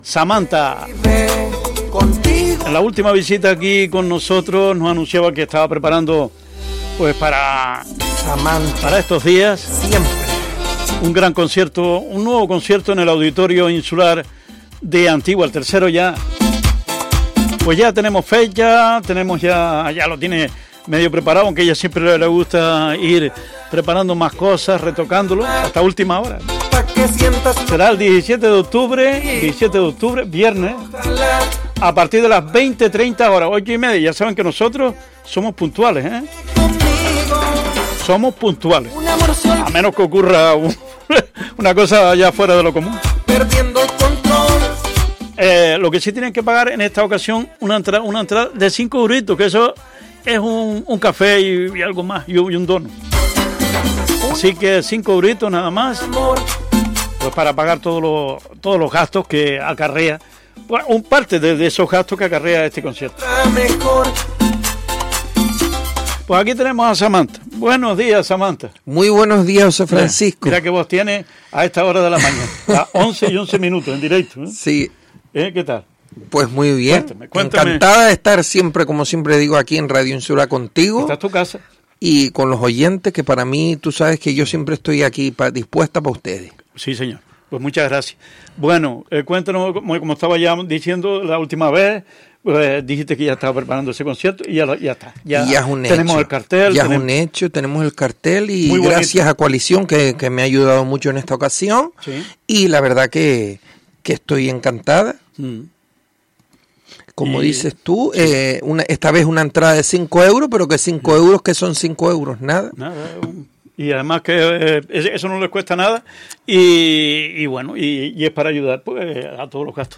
En una entrevista reciente en Radio Insular Fuerteventura , la cantante adelantó detalles de esta cita cultural.